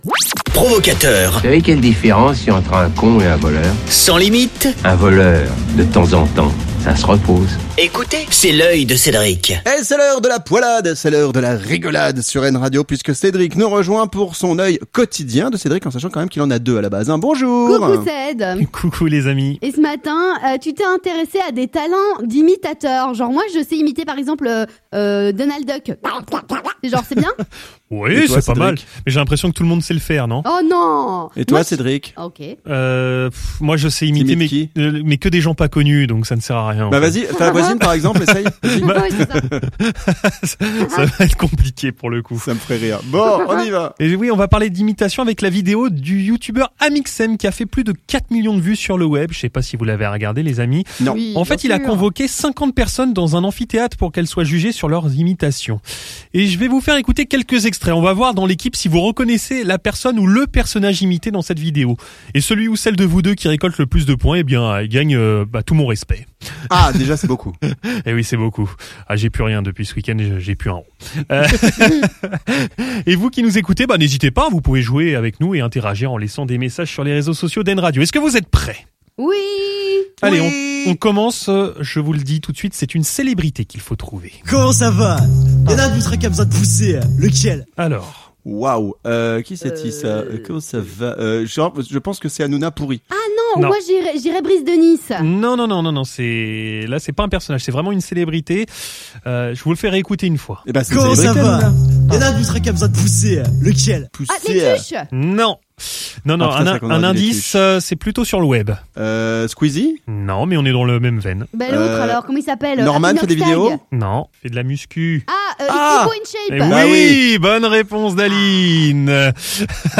Imitations